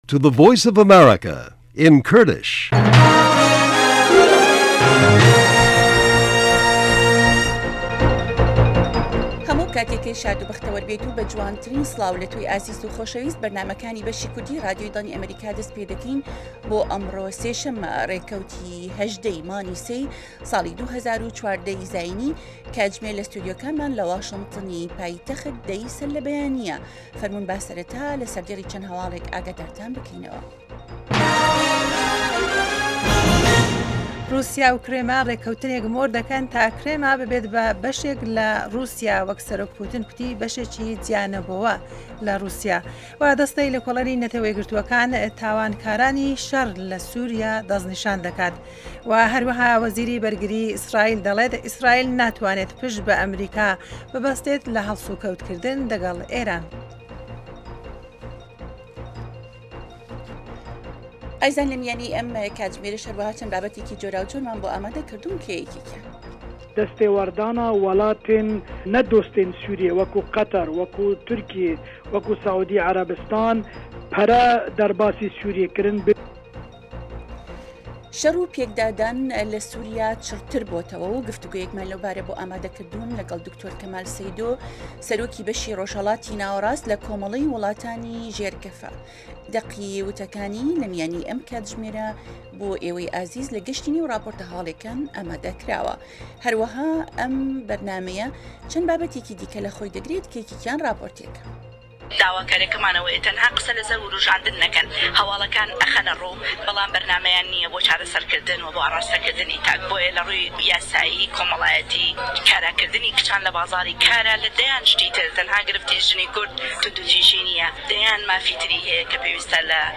بابه‌ته‌كانی ئه‌م كاتژمێره بریتین له هه‌واڵه‌كان، ڕاپۆرتی په‌یامنێران، گفتوگۆ و شیكردنه‌وه، ئه‌مڕۆ له مێژووی ئه‌مه‌ریكادا، هه‌روههاش بابهتی ههمهجۆری هونهری، زانستی و تهندروستی، ئابوری، گهشتێك به نێو ڕۆژنامه جیهانییهكاندا، دیدوبۆچونی واشنتۆن، گۆرانی كوردی و ئهمهریكی و بهرنامهی ئهستێره گهشهكان له ڕۆژانی ههینیدا.